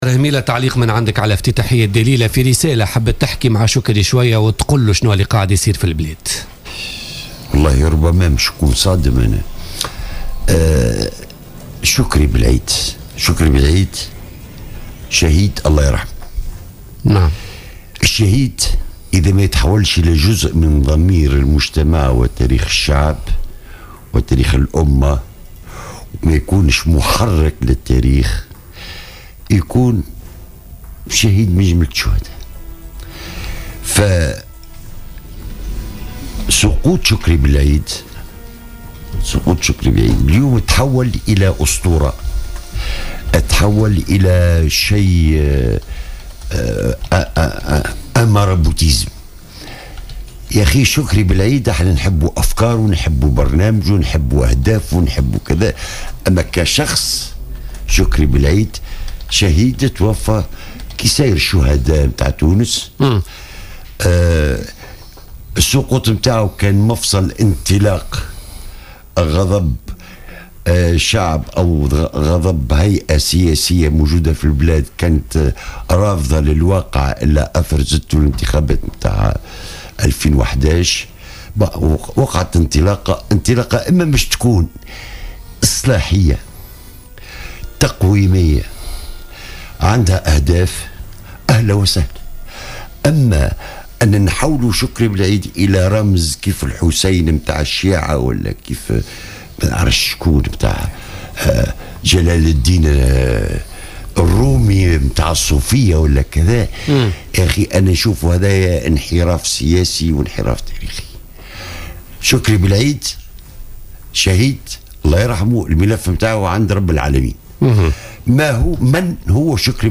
قال رئيس حزب الإقلاع من أجل المستقبل الطاهر هميلة ضيف بوليتيكا اليوم إن شكري بلعيد شهيد من بين الشهداء الذين سقطوا في تونس ولايجب ان يتم تحويله الى أسطورة او رمز.